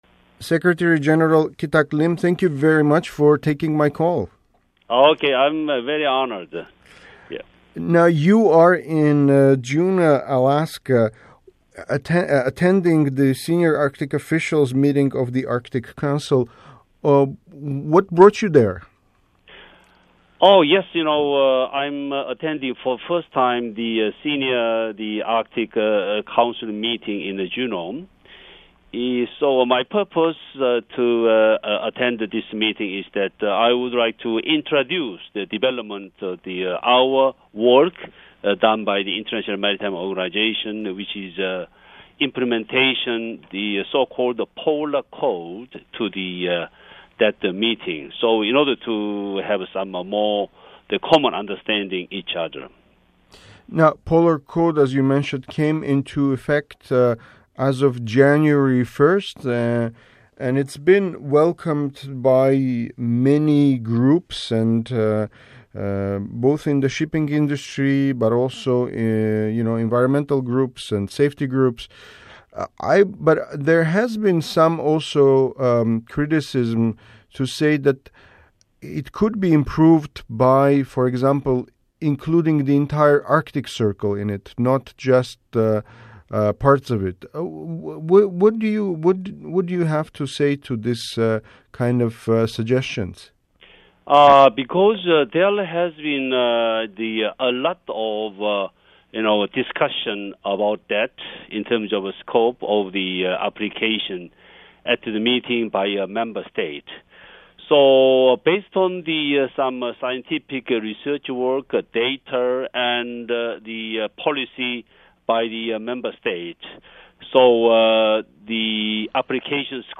Click to listen to the interview with IMO Secretary General Kitack Lim
Speaking on the phone from Juneau, Lim also answered some of the criticism levelled at the maritime code that introduces international safety and environmental standards for vessels operating in Arctic waters.